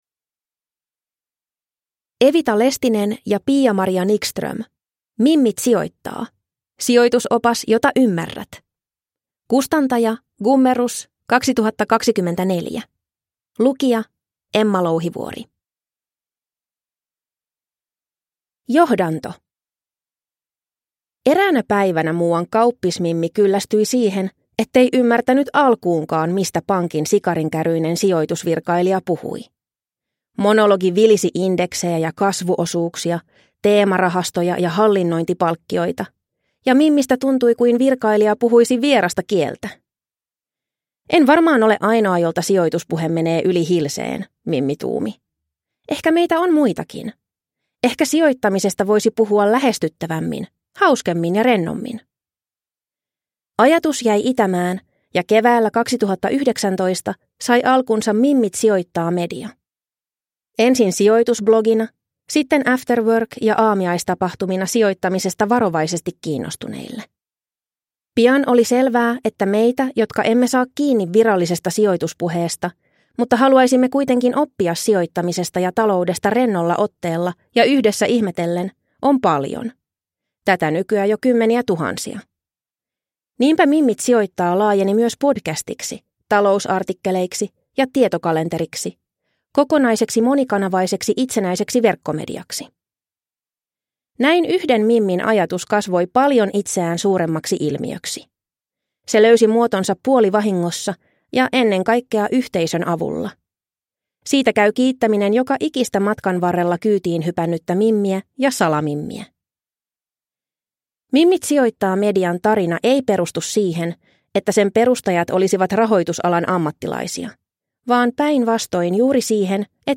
Mimmit sijoittaa - Sijoitusopas – Ljudbok